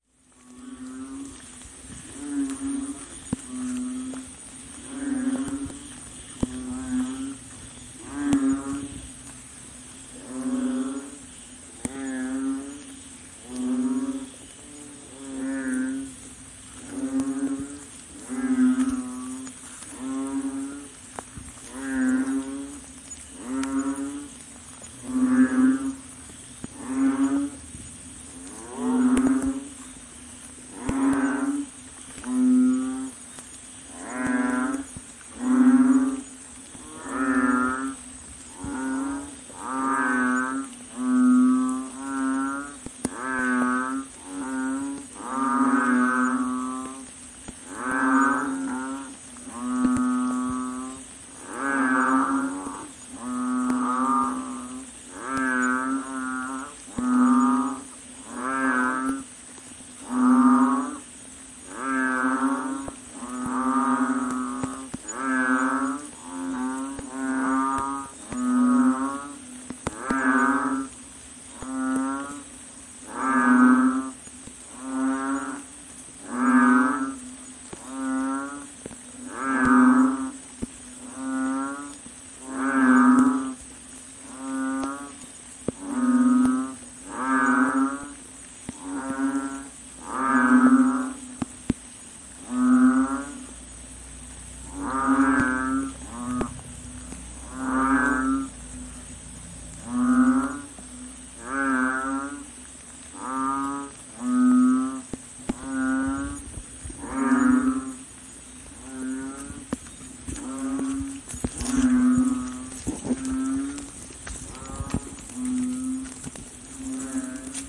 蛙叫声 环境
描述：一只青蛙在一个小池塘里呱呱叫，环境自然的声音。 用Tascam DR05录制
标签： 呱呱叫 呱呱叫 环境 青蛙 蟾蜍 性质 现场记录
声道立体声